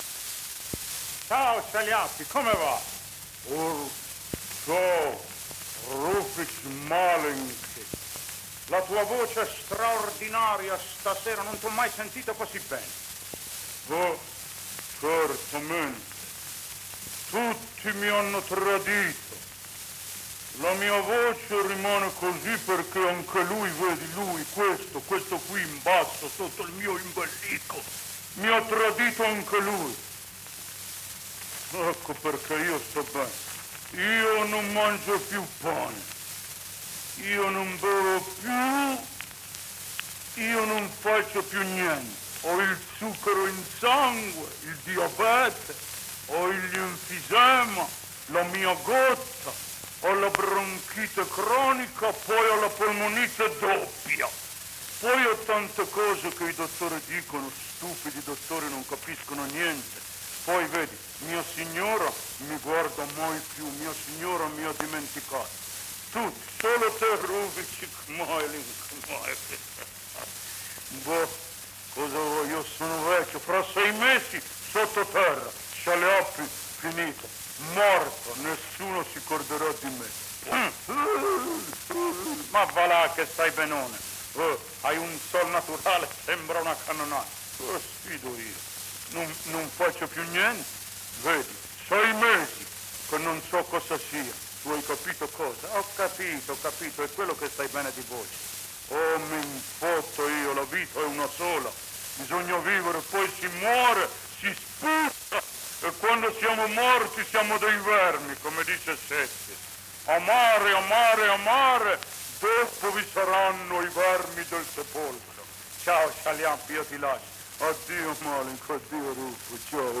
In ultimo, un sonoro dove scherzosamente intervista l’amico Chaliapin, in effetti è sempre lui nella domanda e nella risposta.
RUFFO-TITTA-INTERVISTA-comica.mp3